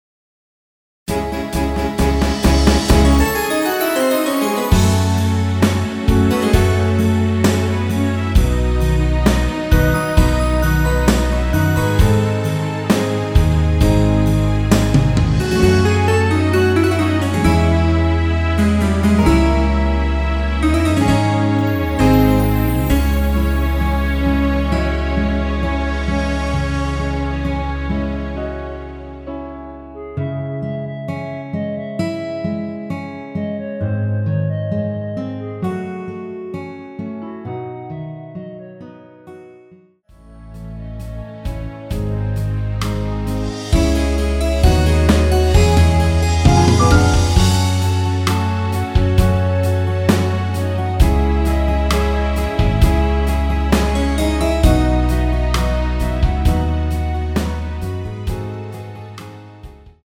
원키 멜로디 포함된 MR입니다.
C#m
앞부분30초, 뒷부분30초씩 편집해서 올려 드리고 있습니다.
(멜로디 MR)은 가이드 멜로디가 포함된 MR 입니다.